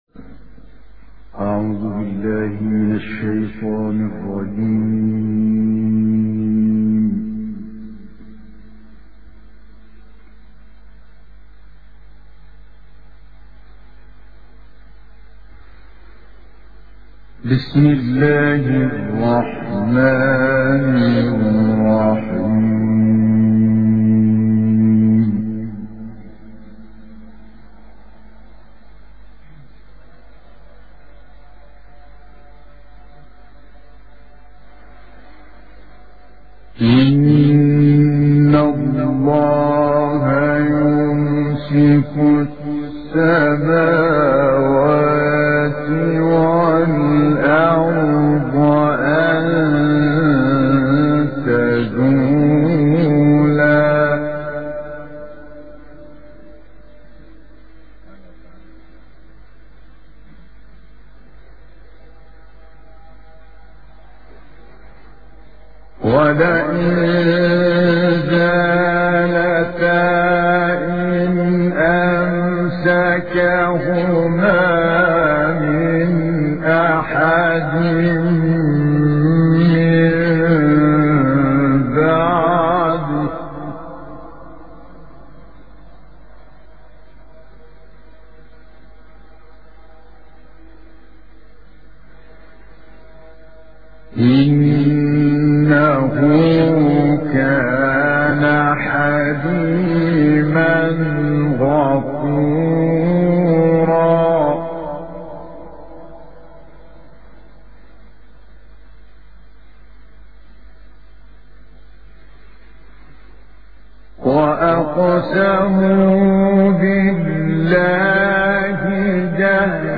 May God make us meet your good expectations - El Forqaan For Recitations and Quran Science